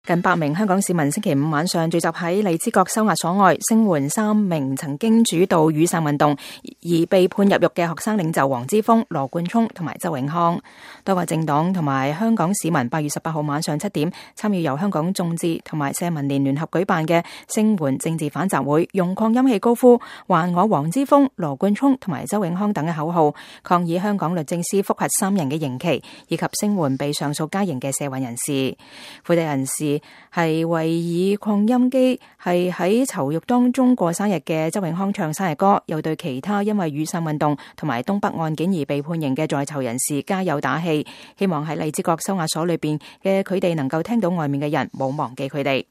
近百名香港市民星期五晚上聚集在荔枝角收押所外，聲援三名曾經主導雨傘運動而被判入獄的學生領袖黃之鋒、羅冠聰和周永康。集會人士為以擴音器為在囚獄中過生日的周永康唱生日歌，又對其他因雨傘運動和東北案件而判刑的在囚人士加油打氣，希望在荔枝角收押所裡的他們能聽到外面的人沒有忘記他們。